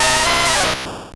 Cri de Racaillou dans Pokémon Or et Argent.